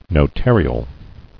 [no·tar·i·al]